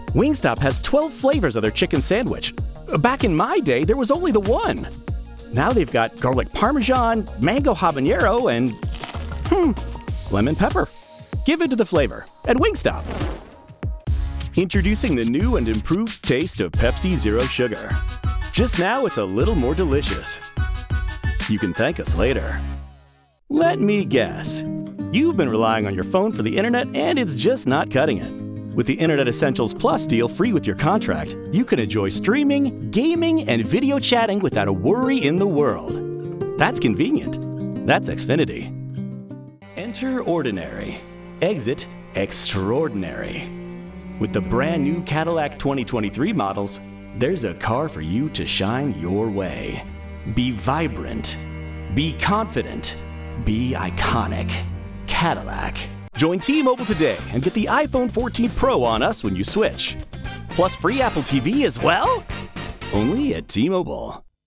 Middle-aged deep-voiced neutral/mid-western US accented voice talent with range on tone and
Commercial Reel - late 2023